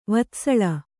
♪ vatsaḷa